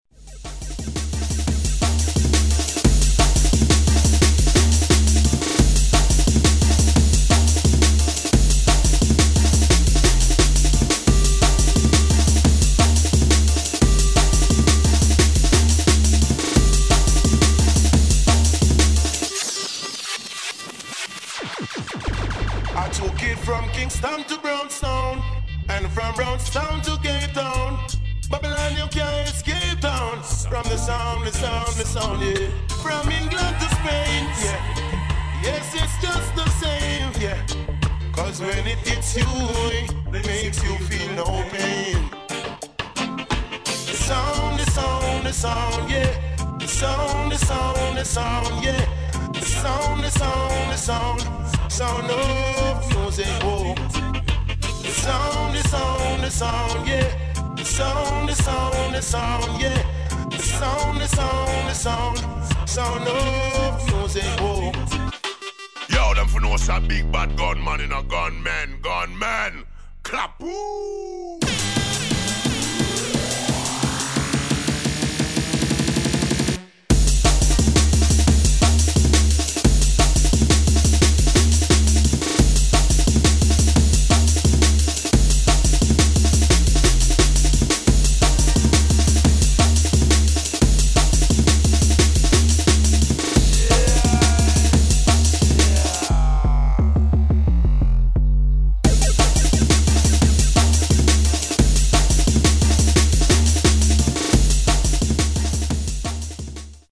DRUM'N'BASS / JUNGLE